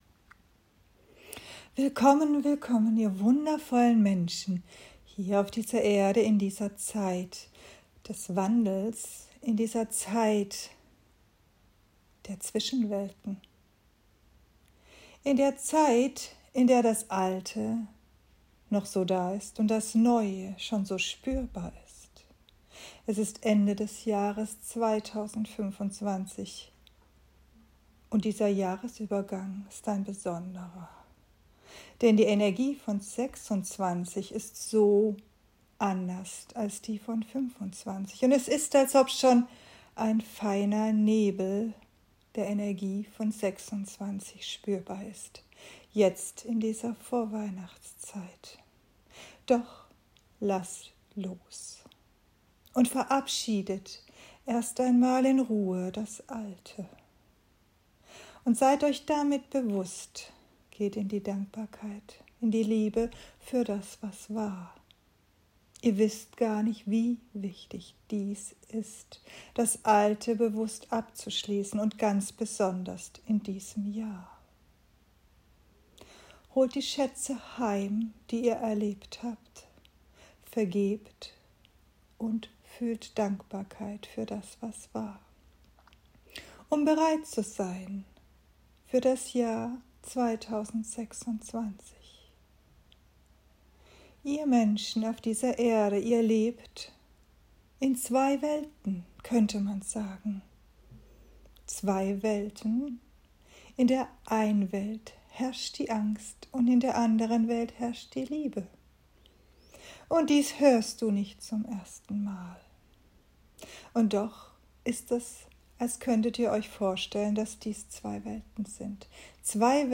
Channeling
Channeling-waehle-die-Liebe.m4a